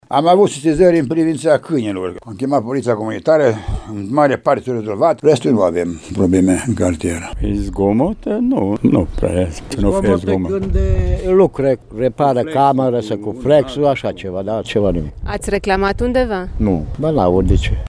Președinții asociațiilor de proprietari și locatarii din Mureș spun că actualmente sunt puține situații în care vecinii se plâng de zgomot: